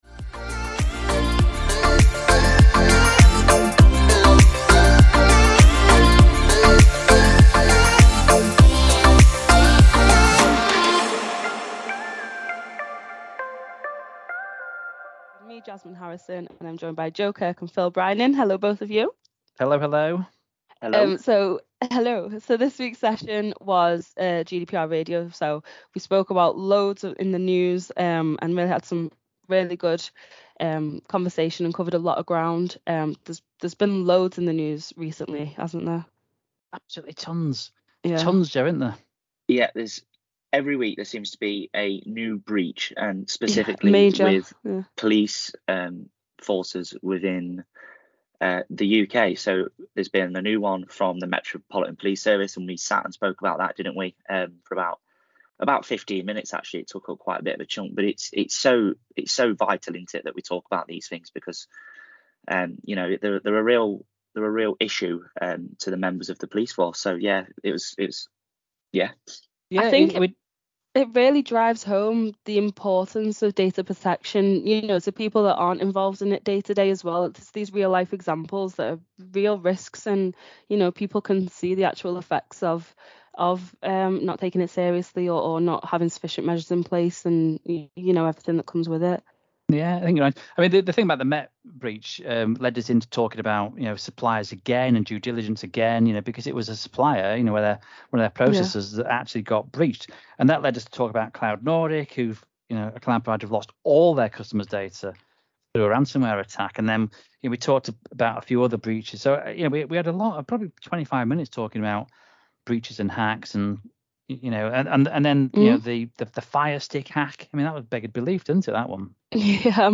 Join our expert hosts as they navigate the evolving landscape of data security and privacy.